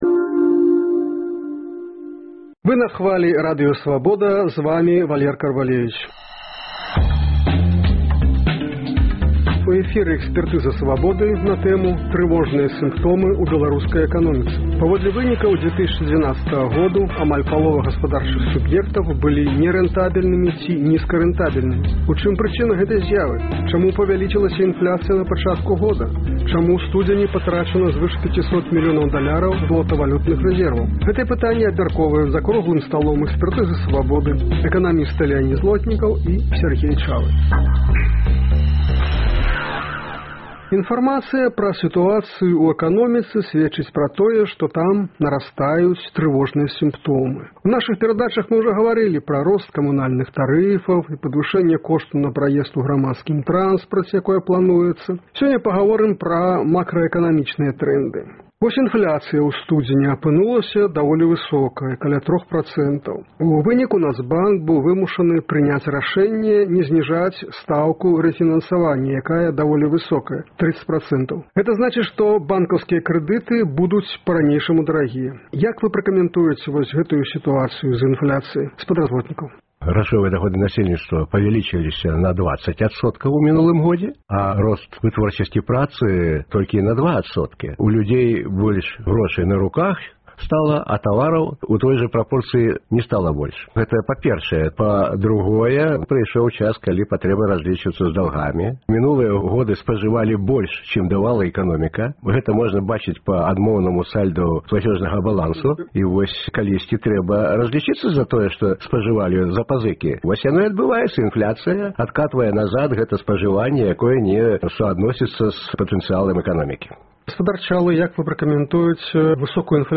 Чаму павялічылася інфляцыя на пачатку года? Гэтыя пытаньні абмяркоўваюць за круглым сталом "Экспэртызы "Свабоды” эканамісты